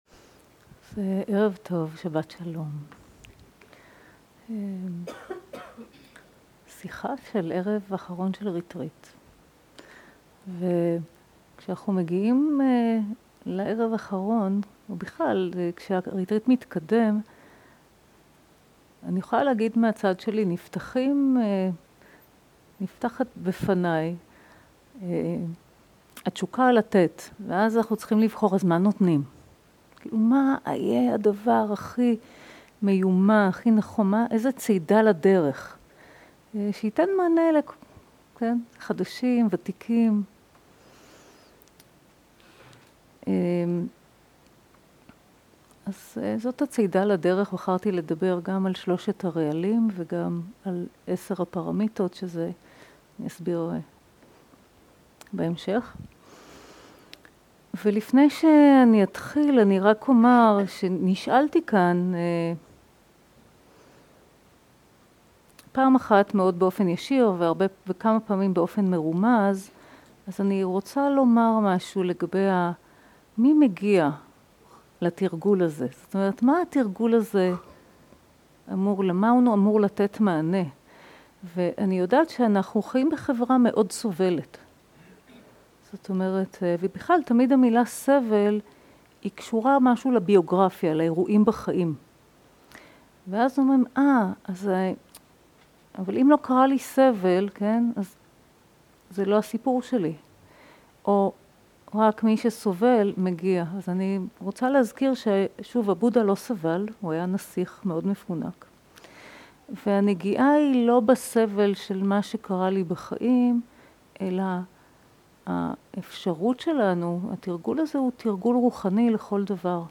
סוג ההקלטה: שיחות דהרמה
עברית איכות ההקלטה: איכות גבוהה תגיות